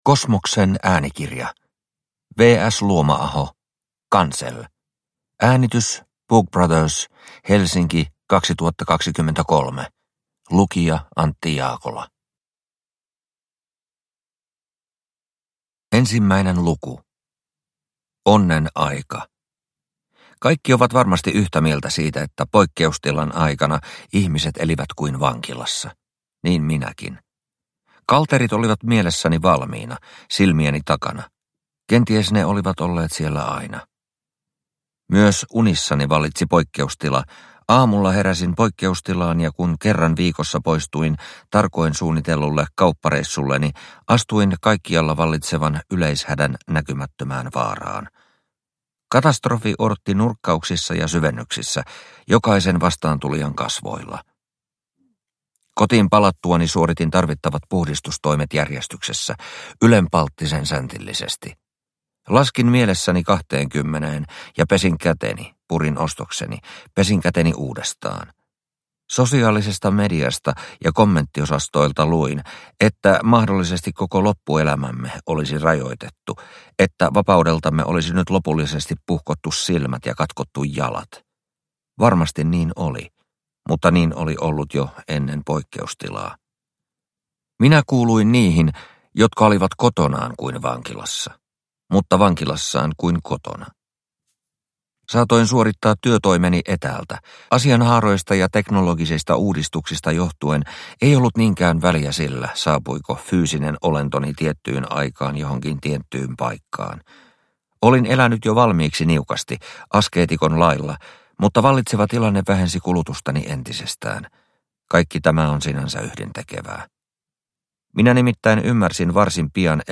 cancel – Ljudbok – Laddas ner